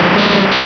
Cri de Grolem dans Pokémon Rubis et Saphir.